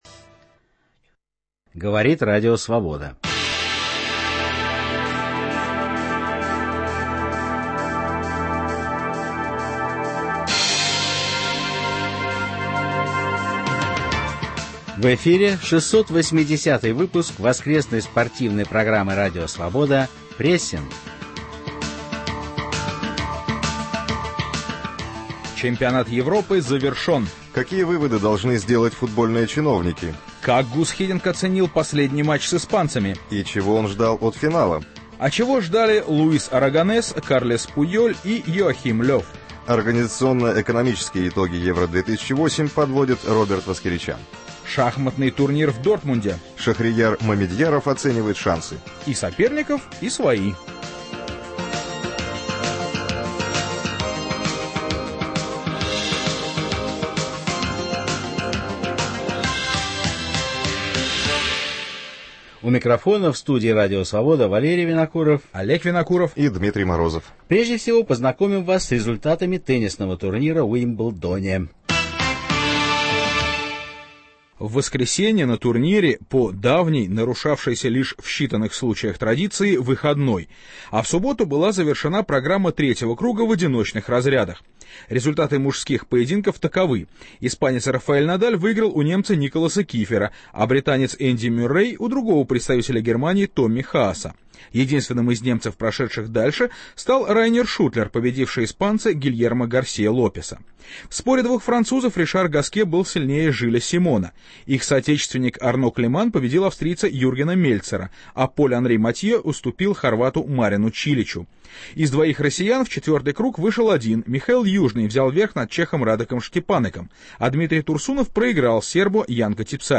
В спортивной программе "Прессинг" - не только свежая информация, анализ и размышления, но и голоса спортсменов и тренеров всего мира с откровениями о жизни, о партнерах и соперниках. Речь не только о самом спорте, ибо он неотделим от социальных, экономических, нравственных и национальных проблем.